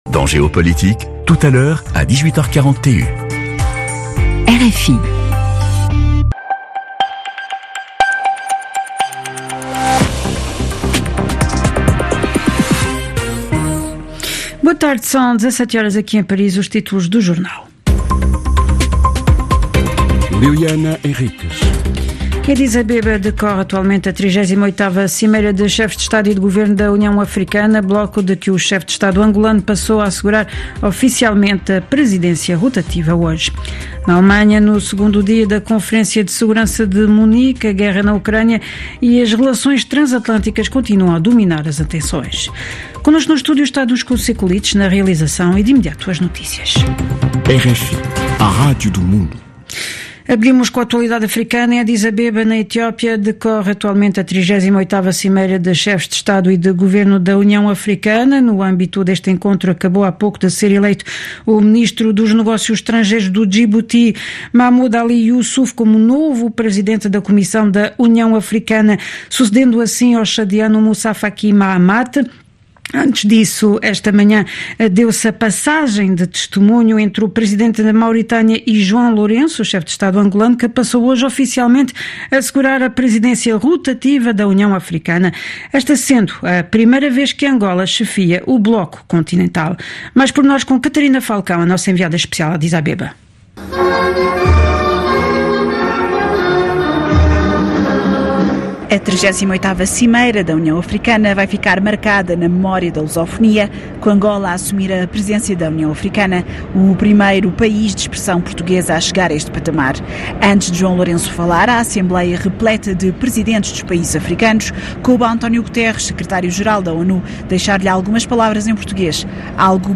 Noticiário 15/02 16h00 TMG.